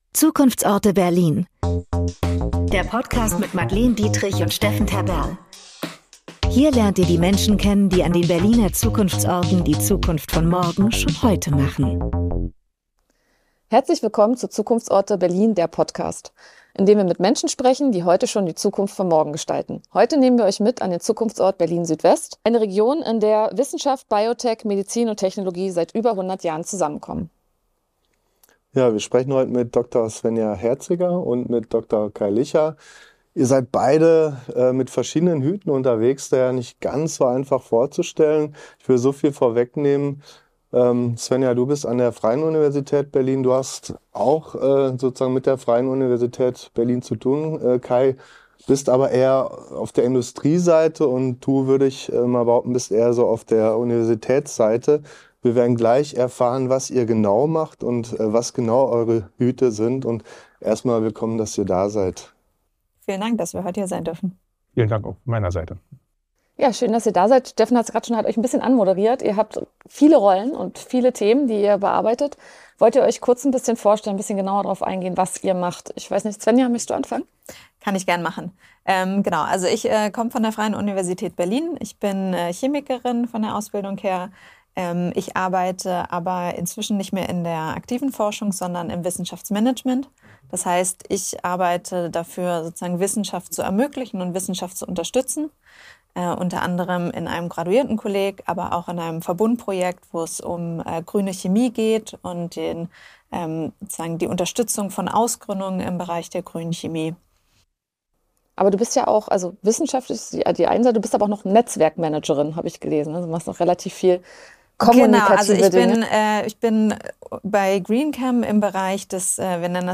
Außerdem geht es darum, wie Wissenschaft, Startups und Industriepartner zusammenfinden, welche Infrastruktur und Expertise dafür nötig sind und warum frühe Validierung und klare Anwendungsfälle entscheidend sind. Ein Gespräch über Deep-Tech-Realität, Zusammenarbeit auf Augenhöhe und den Aufbau eines Ökosystems, das Gründungen und Wachstum aus der Forschung heraus möglich macht.